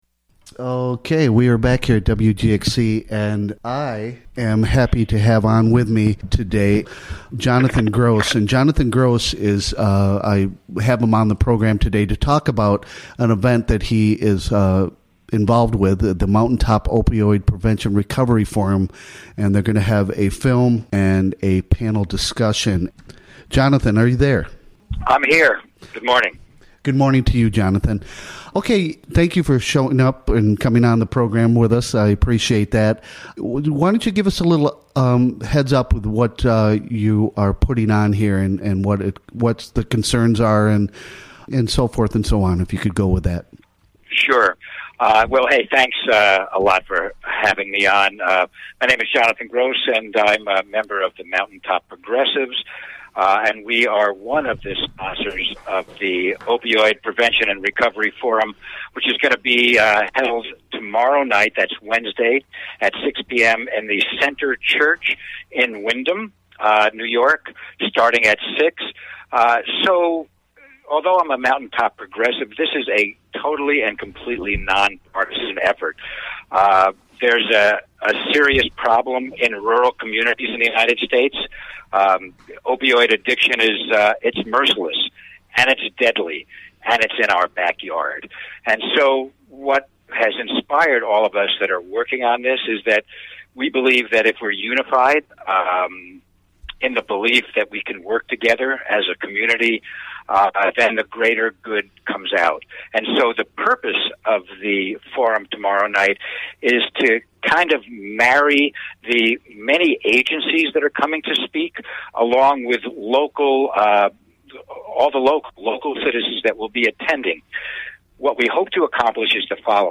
Recorded during the WGXC Morning Show of Tuesday, May 15, 2018.